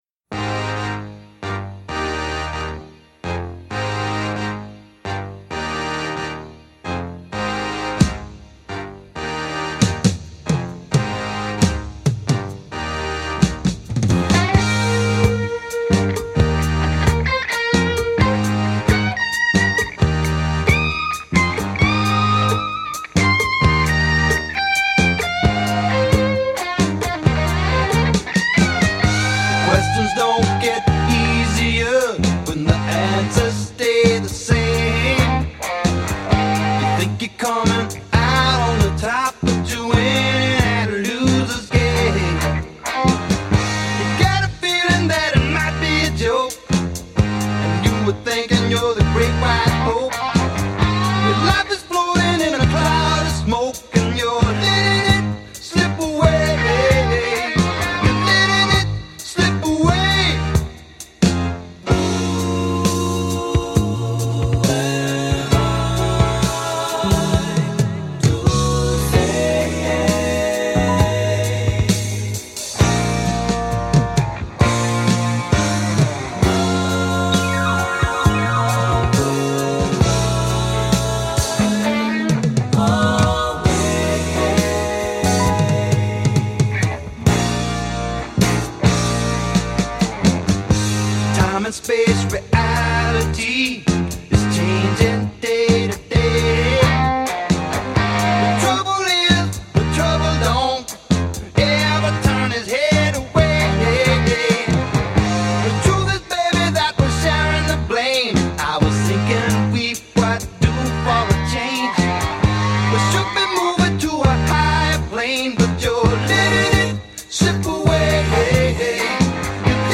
Recorded at Autumn Sound, Dallas.